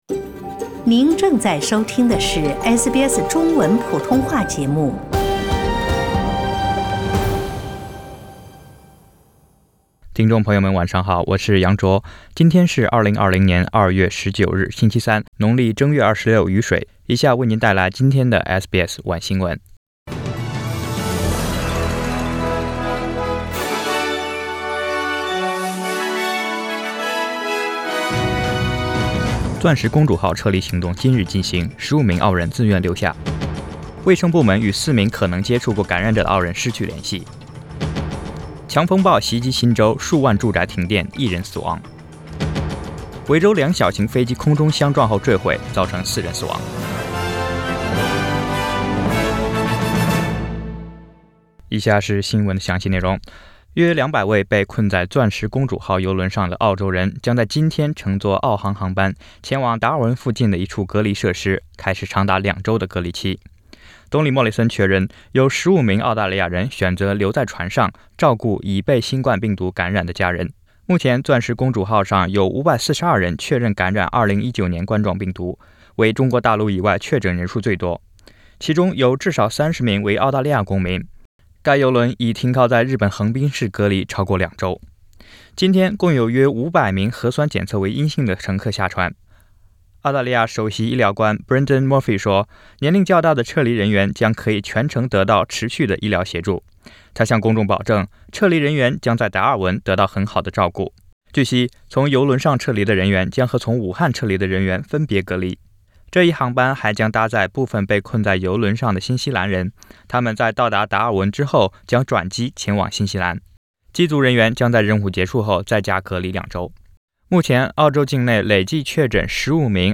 SBS晚新闻（2月19日）